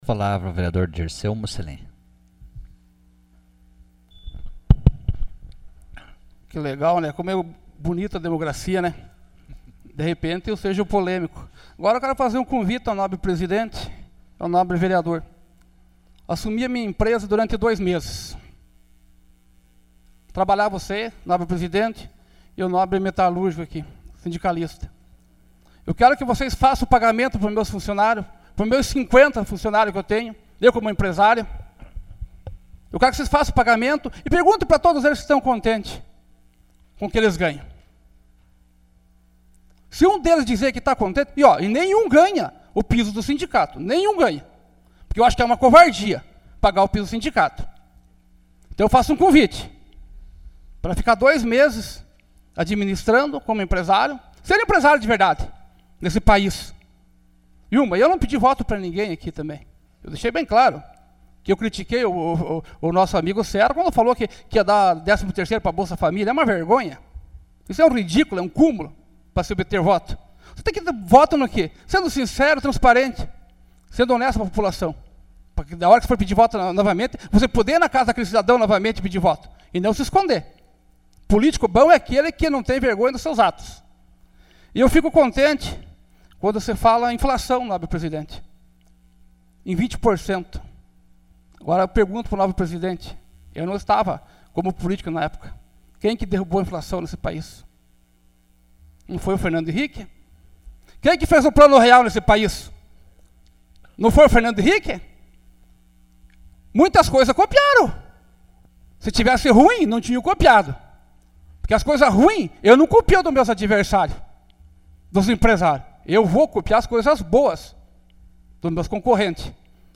Pronunciamento pessoal.